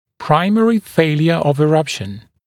[‘praɪmərɪ ‘feɪljə əv ɪ’rʌpʃn][‘праймэри ‘фэйлйэ ов и’рапшн]первичное нарушение прорезывания, первичное непрорезывание